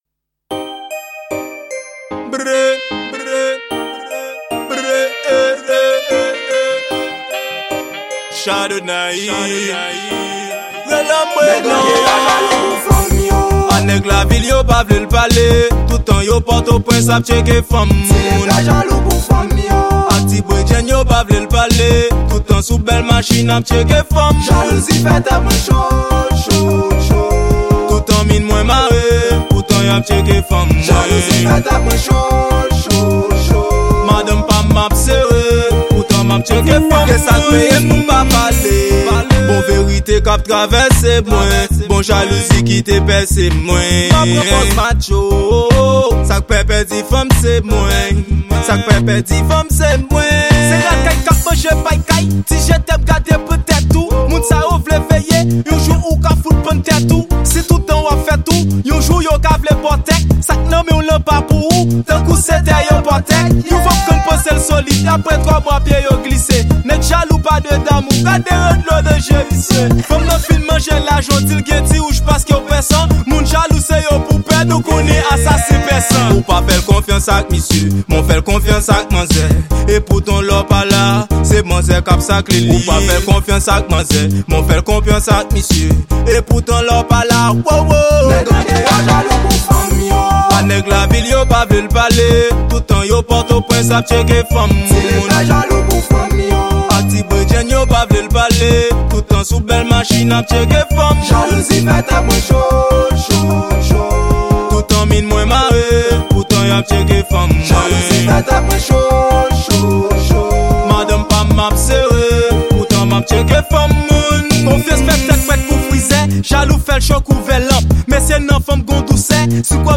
Genre: Raggae.